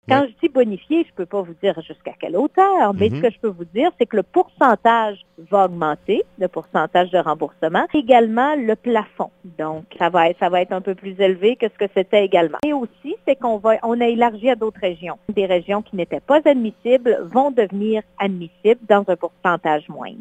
Le premier ministre, Philippe Couillard, annonçait six gestes pour améliorer les conditions du transport aérien en région, en clôture du Sommet tenu à Lévis, vendredi.
D’autre part, la ministre déléguée au Transport, Véronyque Tremblay, souligne que le Programme de réduction des tarifs aériens, qui accorde déjà à un Madelinot un remboursement de 30 pour cent jusqu’à un maximum de 700 dollars par année, sera bonifié :